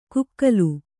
♪ kukkalu